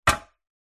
Звуки краски
Банка с краской стоит на бетонном полу